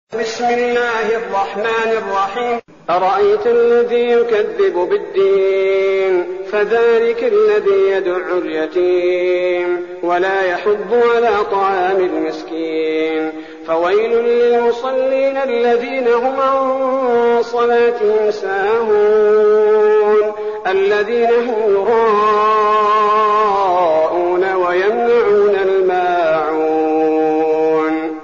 المكان: المسجد النبوي الشيخ: فضيلة الشيخ عبدالباري الثبيتي فضيلة الشيخ عبدالباري الثبيتي الماعون